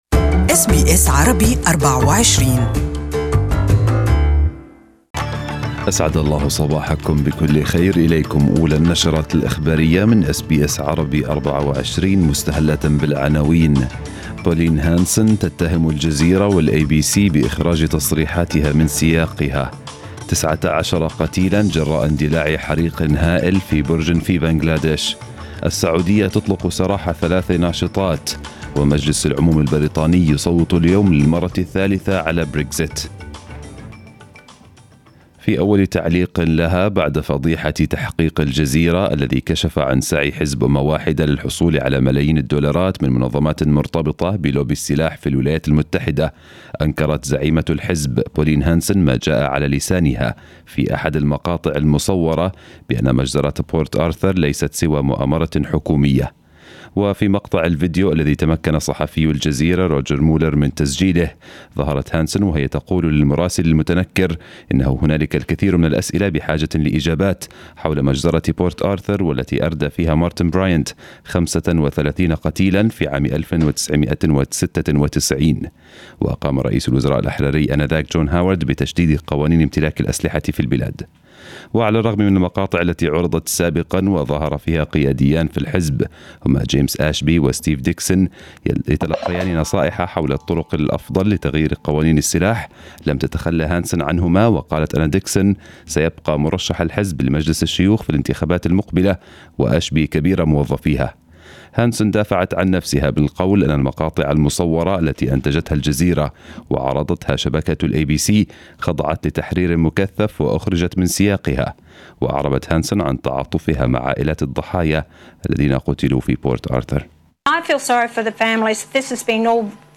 News bulletin of the day in Arabic